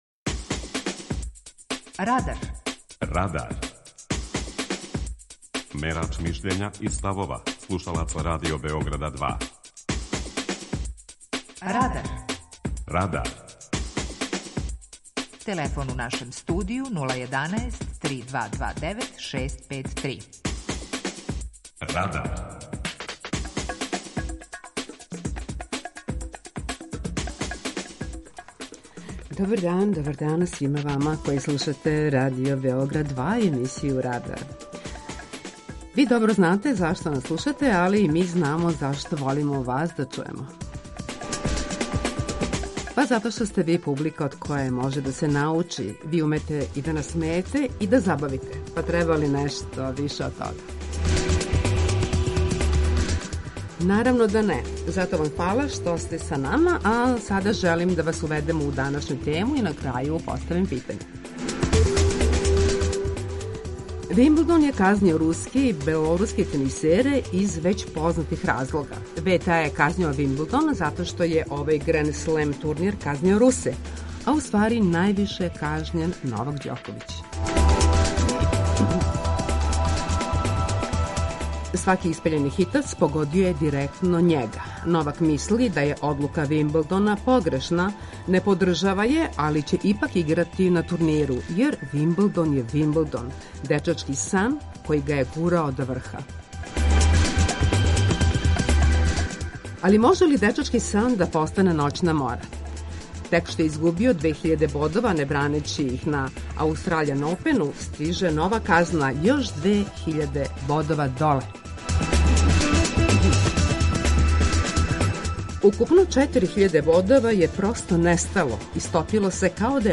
Вимблдон - од дечачког сна до ноћне море преузми : 19.05 MB Радар Autor: Група аутора У емисији „Радар", гости и слушаоци разговарају о актуелним темама из друштвеног и културног живота.